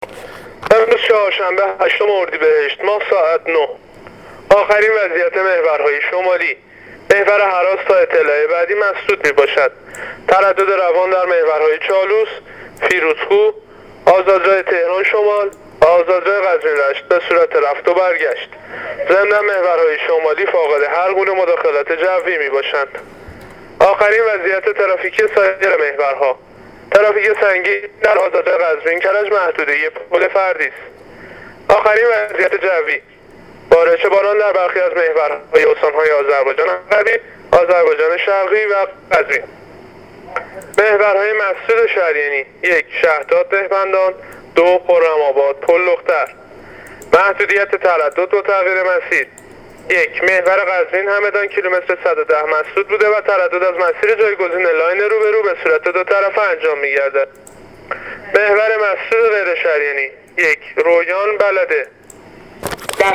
گزارش رادیو اینترنتی از آخرین وضعیت ترافیکی جاده‌ها تا ساعت ۹ هشتم اردیبهشت